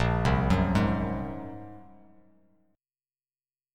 Bb7sus2#5 chord